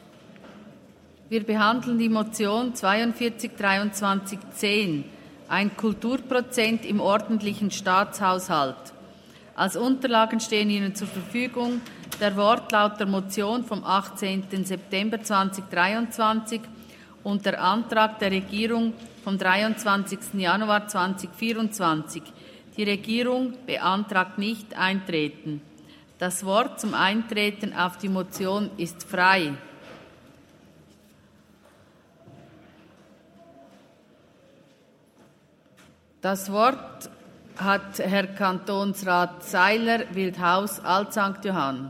Session des Kantonsrates vom 29. April bis 2. Mai 2024, Aufräumsession
1.5.2024Wortmeldung
Dürr-Gams, Ratsvizepräsidentin: Die Regierung beantragt Nichteintreten auf die Motion.